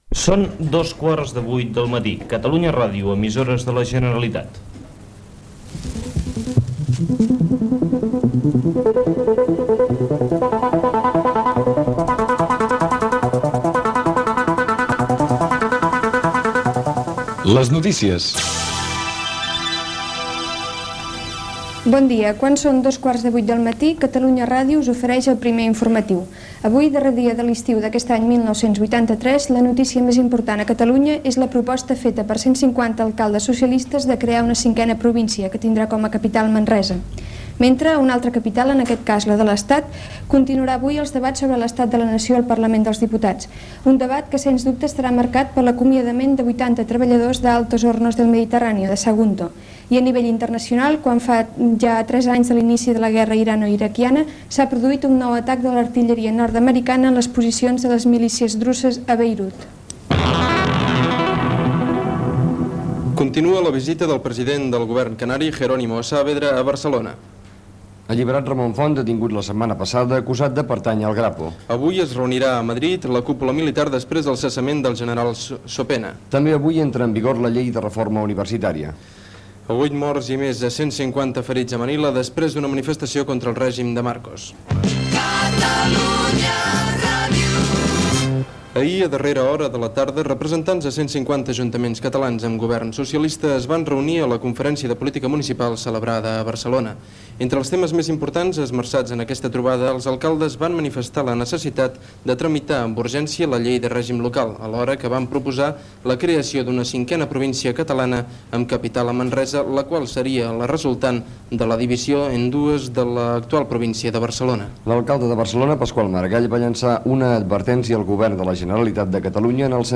f9c6a6c249f9e648edc1714e2c4a0d194d2ff6c3.mp3 Títol Catalunya Ràdio Emissora Catalunya Ràdio Cadena Catalunya Ràdio Titularitat Pública nacional Nom programa Les notícies (Catalunya Ràdio) Descripció Primer informatiu de Catalunya Ràdio.
Resum informatiu.